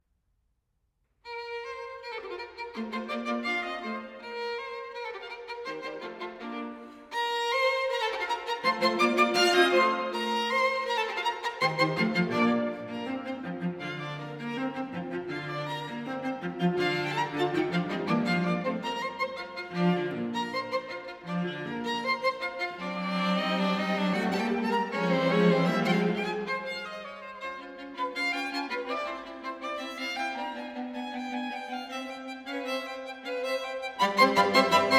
Жанр: Классика
Chamber Music